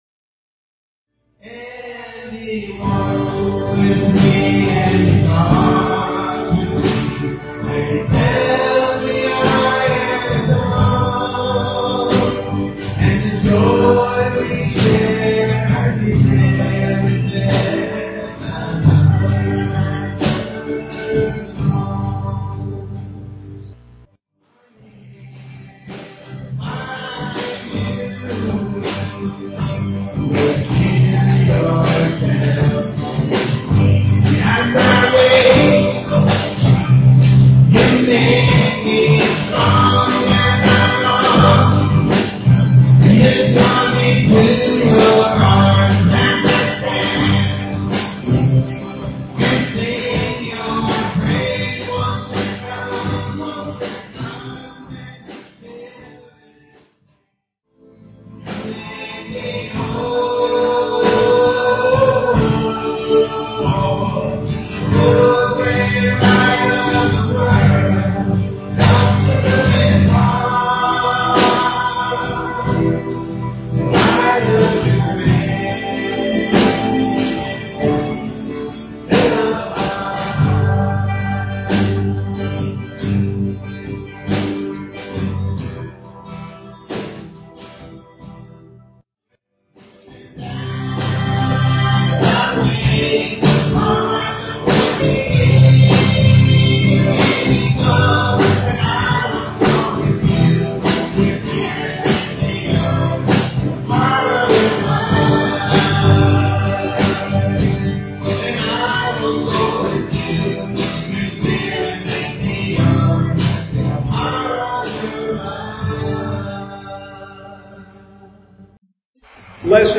PLAY Lessons of Love, Marriage, Motherhood, May 8, 2011 Scripture: Ruth 1-2. Message
at Ewa Beach Baptist Church